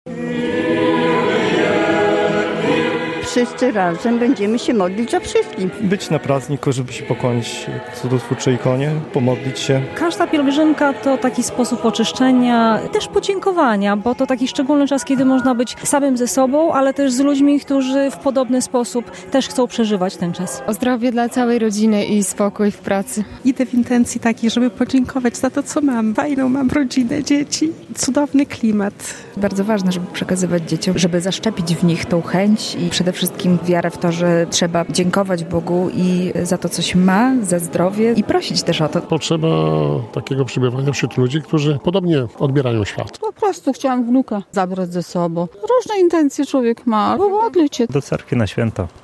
Wszyscy razem będziemy się modlić za wszystkich, trzeba być na prazdniku, żeby się pokłonić cudotwórczej ikonie, pomodlić się. Każda pielgrzymka to taki sposób oczyszczenia i też podziękowania, czas, kiedy można być samym ze sobą, ale też z ludźmi, którzy w podobny sposób chcą przeżyć to święto. Idę w intencji takiej, żeby podziękować za to, co mam, fajną mam rodzinę, dzieci, cudowny tu klimat, bardzo ważne, żeby tradycję przekazywać dzieciom, zaszczepić w nich tą chęć, wiarę, za wszystko dziękować Bogu, za zdrowie i prosić też o to, chciałam wnuka zabrać ze sobą, różne intencje człowiek ma, pomodlić się, idę do cerkwi na święto - mówią uczestnicy pielgrzymki.